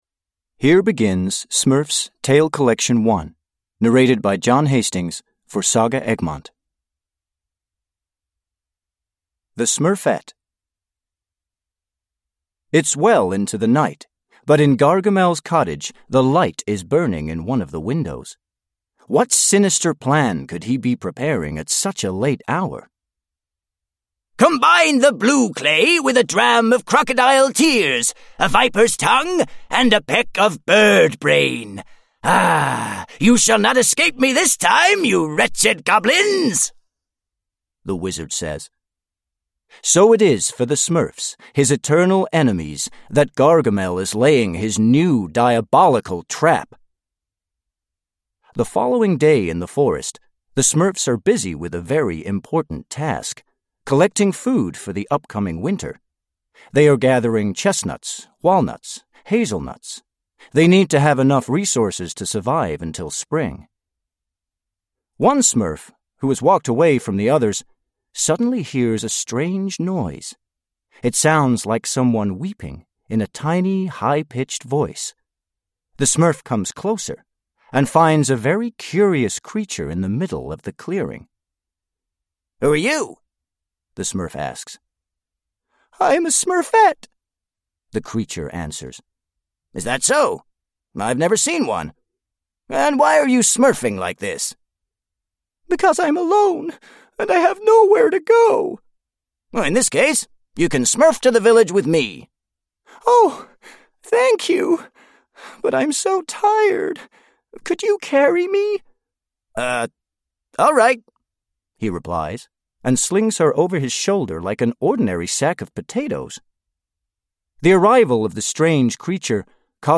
Smurfs: Storytime Collection 1 (ljudbok) av Peyo